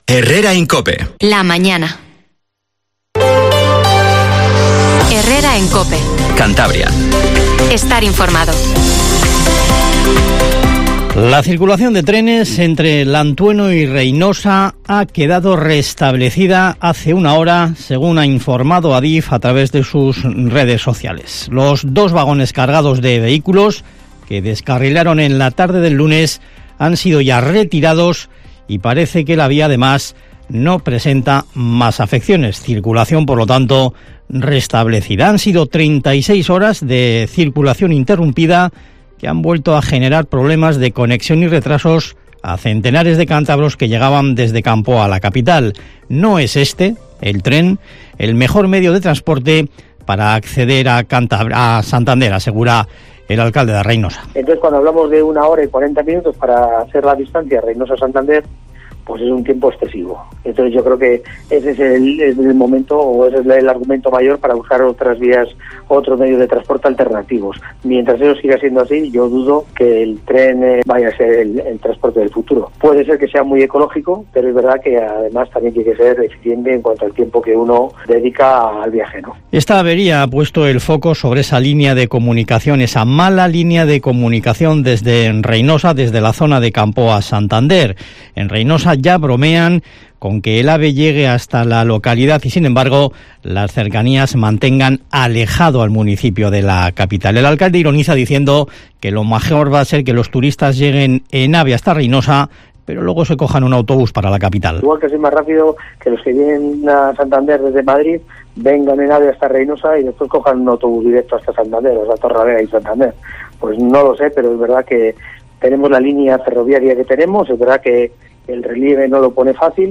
Informativo HERRERA en COPE CANTABRIA 07:20